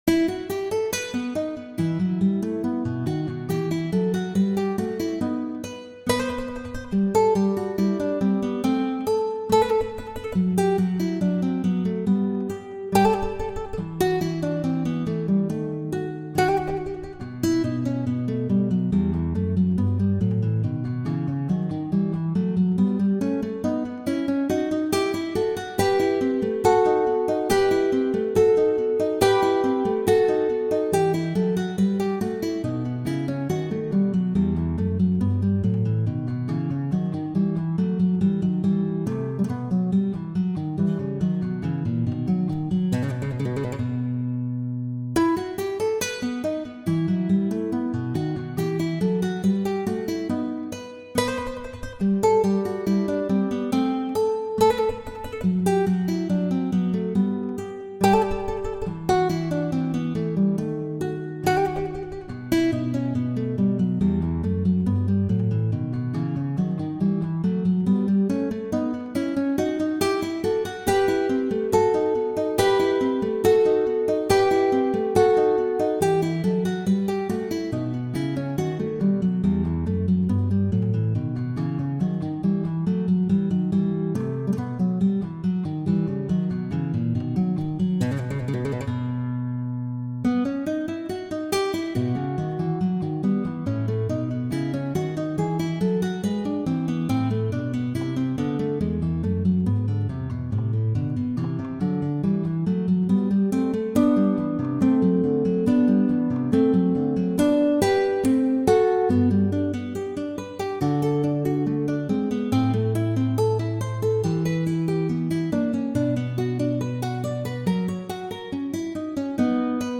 Sonate en mi mineur (K1).pdf
Sonate-K1-en-mi-mineur.mp3